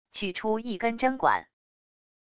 取出一根针管wav音频